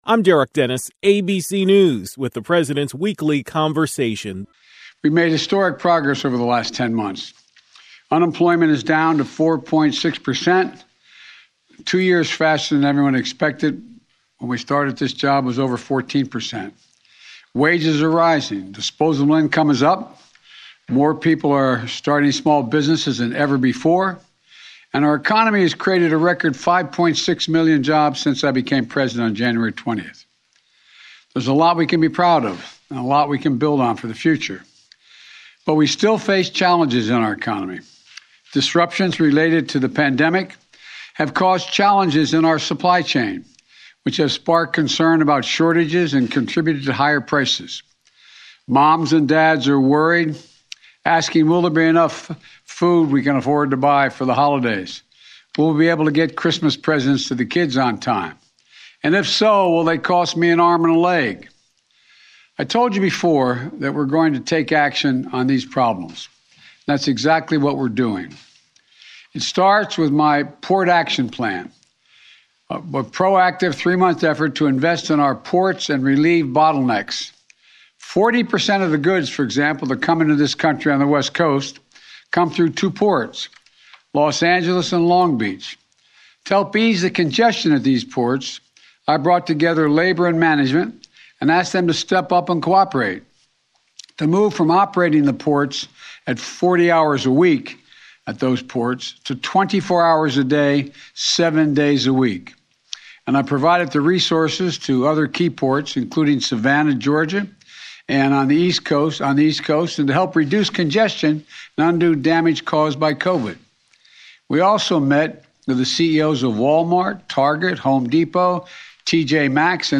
President Biden discussed the current state of the economy in the South Court Auditorium.